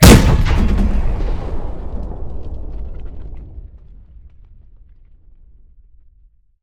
tank-cannon-4.ogg